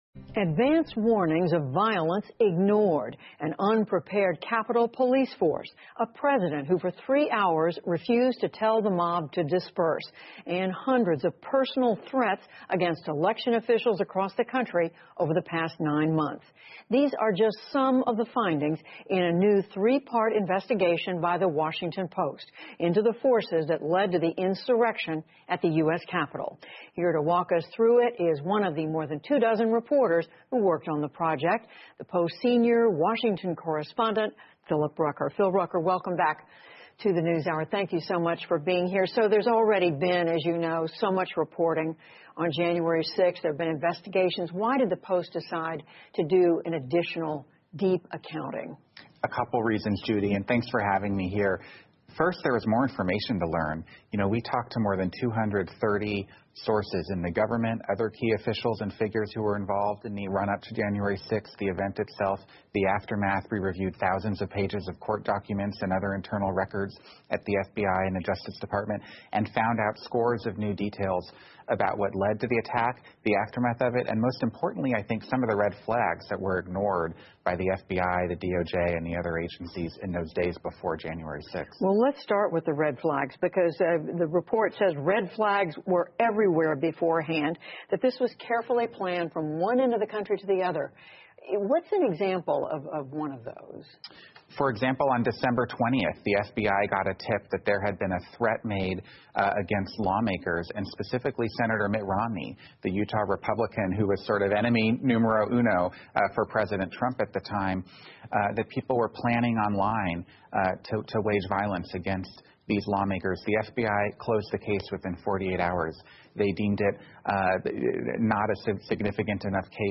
PBS高端访谈:为什么美国国会发生暴乱 听力文件下载—在线英语听力室